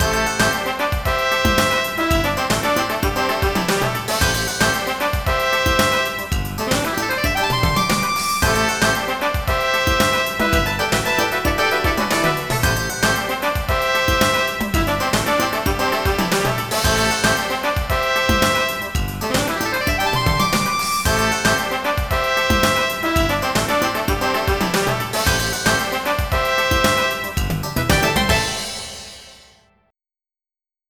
MIDI Music